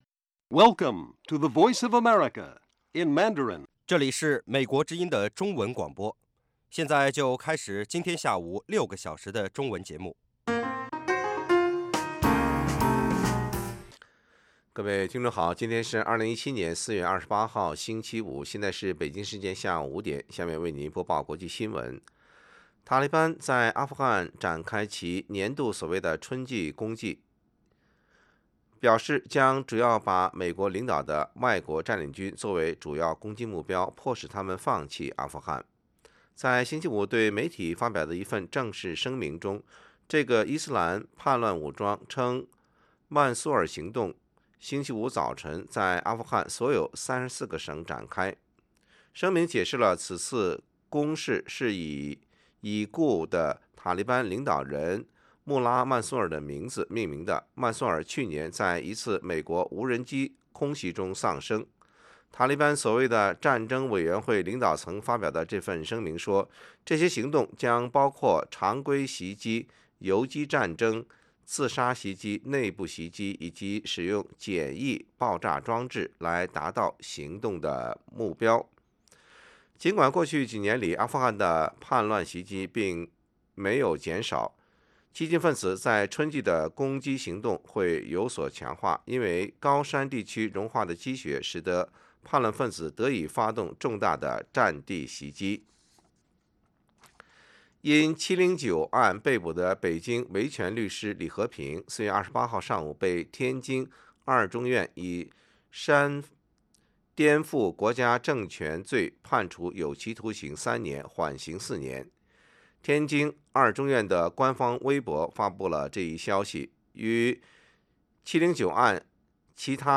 北京时间下午5-6点广播节目。广播内容包括国际新闻，美语训练班(学个词， 美国习惯用语，美语怎么说，英语三级跳， 礼节美语以及体育美语)，以及《时事大家谈》(重播)